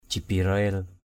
/ʥi-bi-ra-e̞l/ (d.) thiên thần Gabriel = l’ange Gabriel.